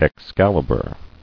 [Ex·cal·i·bur]